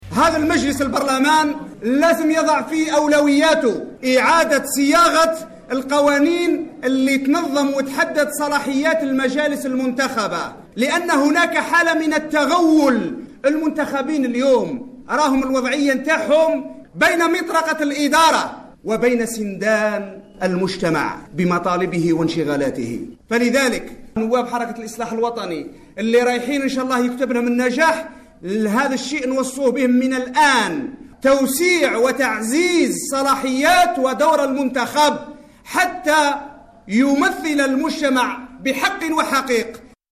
Fillali Ghouini, président d'El Islah au micro de la radio Chaine 3